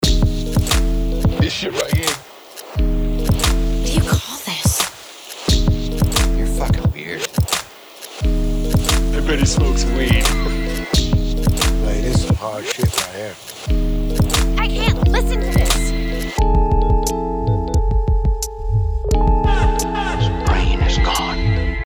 New beat